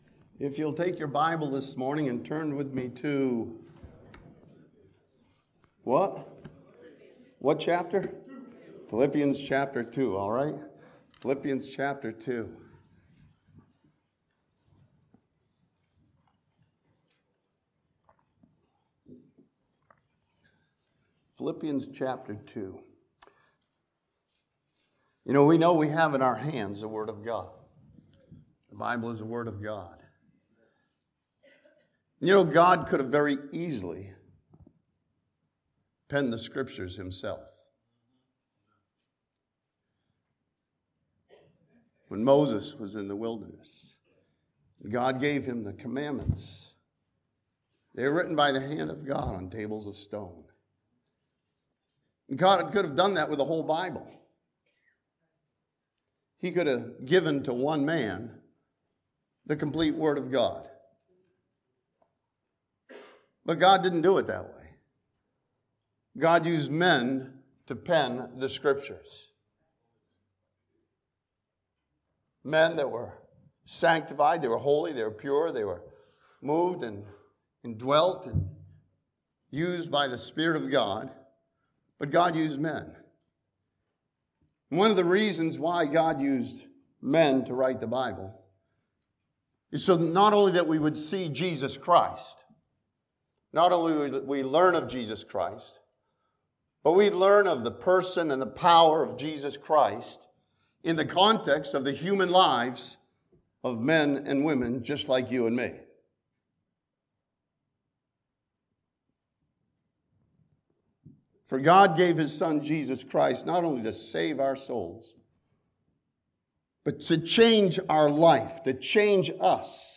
This sermon from Philippians chapter 2 gives every Christian multiple reasons to be always rejoicing in Christ.